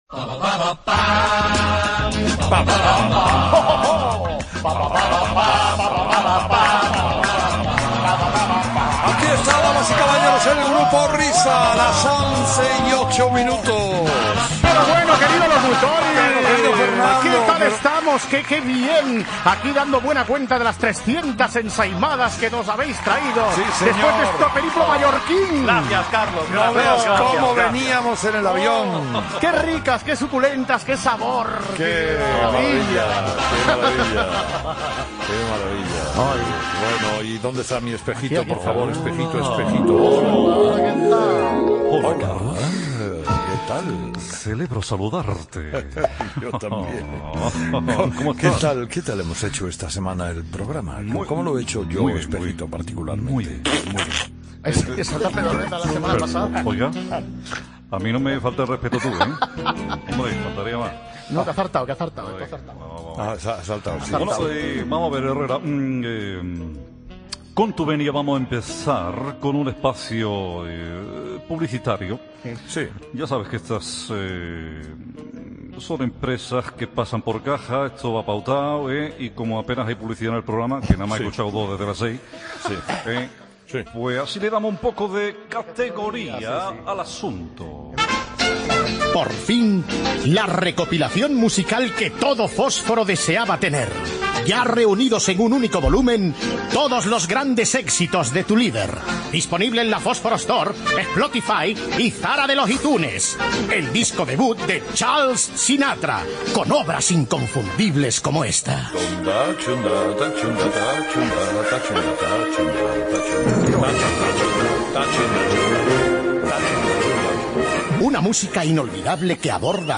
Espai fet pel Grupo Risa amb "El espejo" on la imitació de Carlos Herrera dialoga amb ell i presenta el disc del locutor
Info-entreteniment